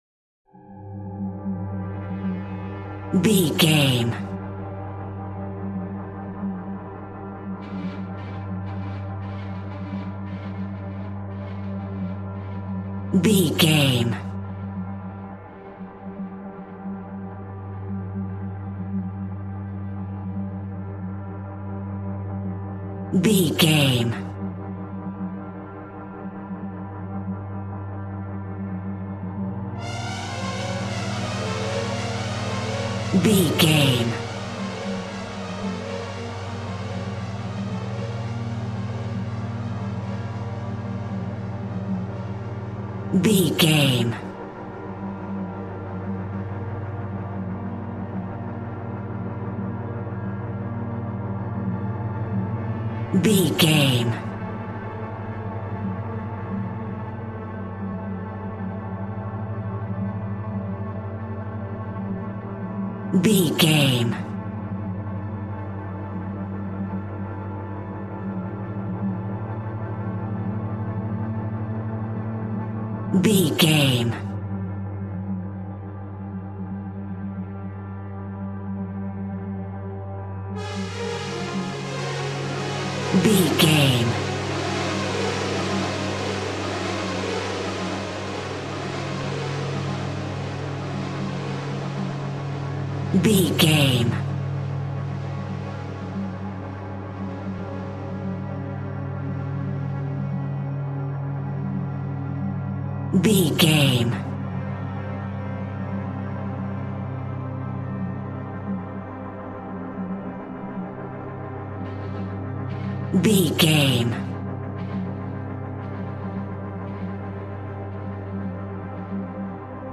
Thriller
Atonal
Slow
scary
tension
ominous
dark
suspense
eerie
synthesiser
Horror Ambience
dark ambience
Synth Pads
Synth Ambience